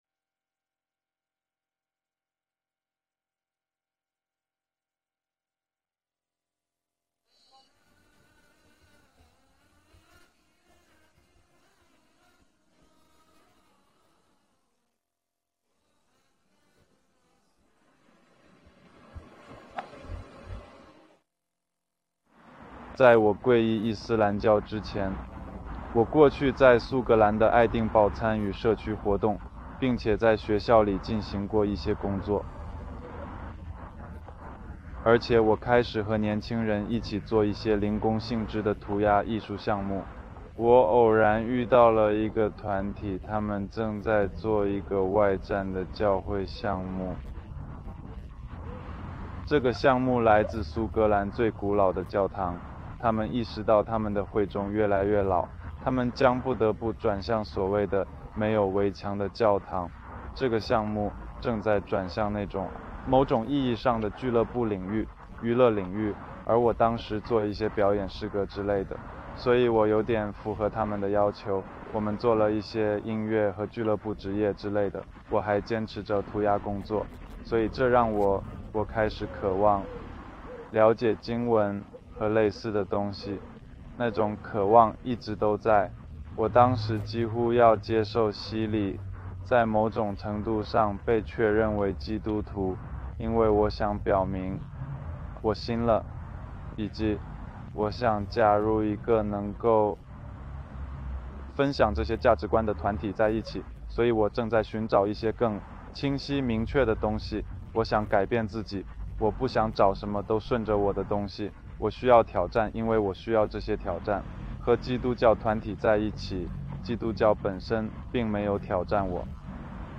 视频 新穆斯林故事 男性